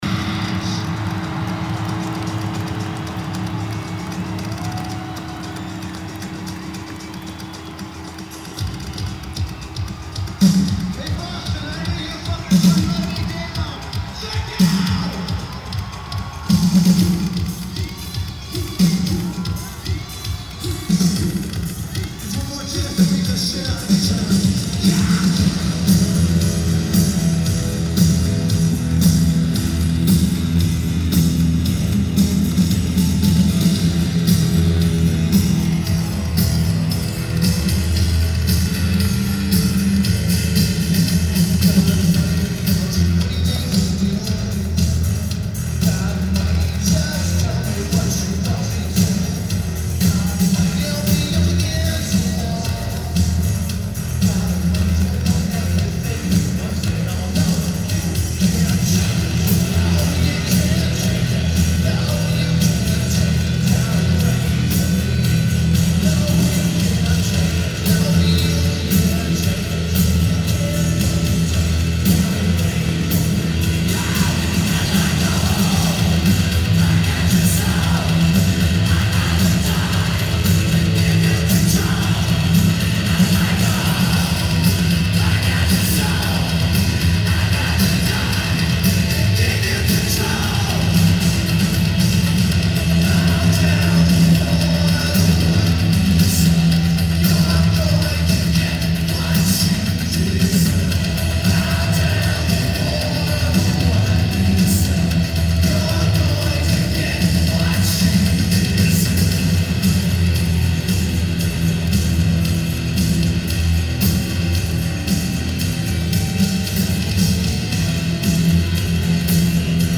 Boston Garden